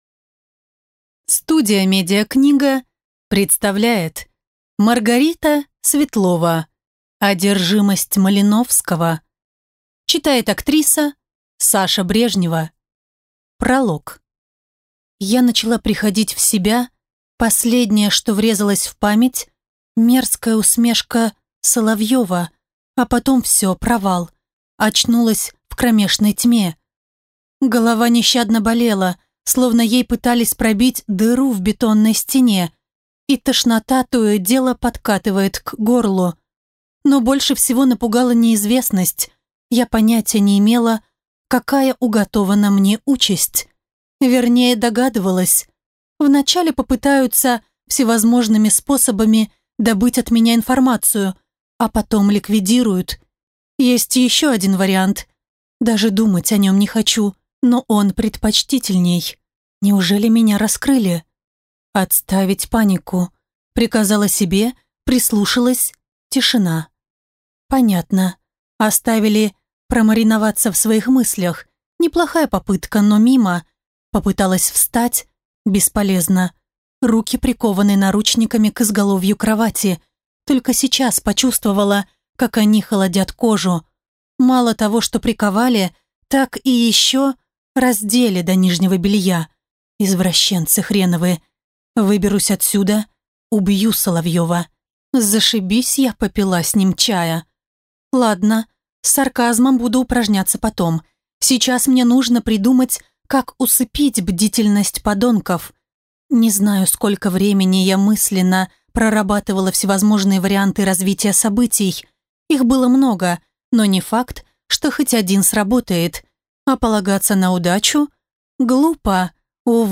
Аудиокнига Одержимость Малиновского | Библиотека аудиокниг